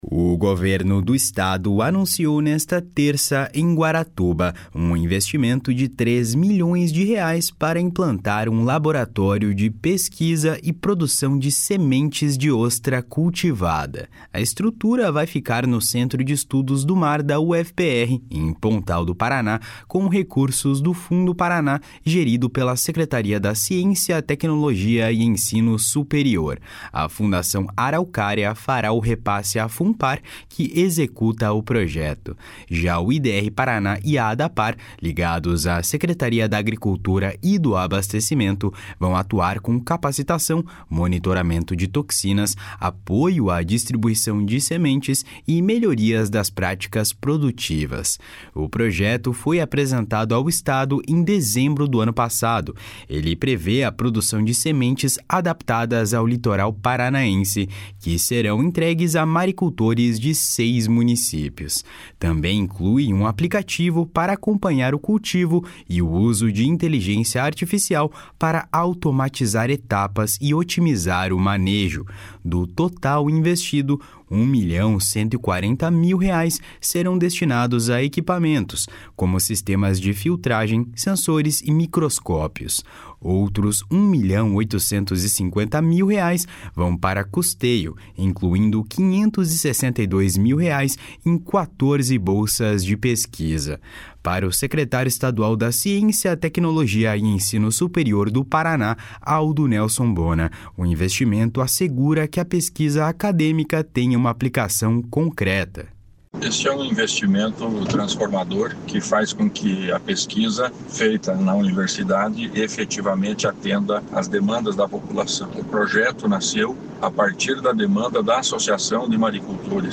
// SONORA ALDO BONA //